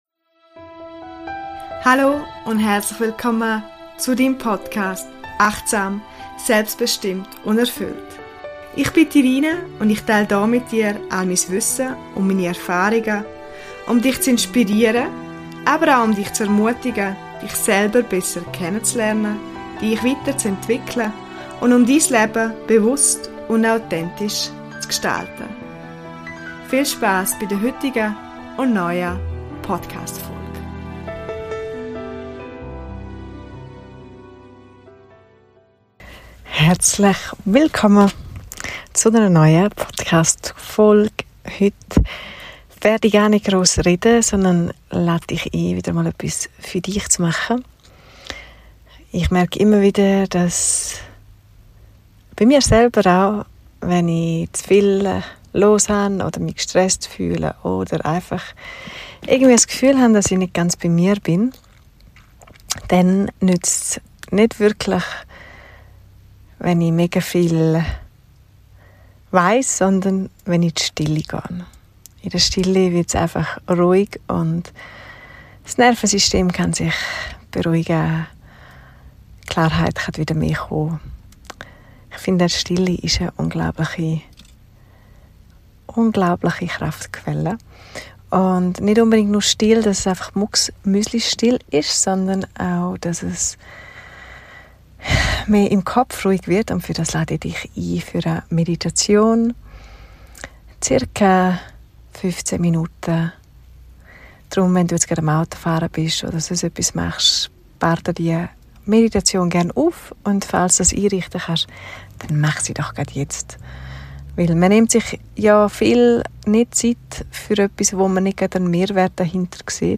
40: Eine ruhige Meditation für einen klaren Start in den Tag, zum Loslassen am Abend oder wann immer du dich zentrieren und mit dir selbst verbinden möchtest.